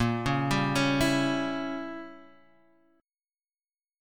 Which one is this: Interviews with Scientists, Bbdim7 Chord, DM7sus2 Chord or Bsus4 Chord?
Bbdim7 Chord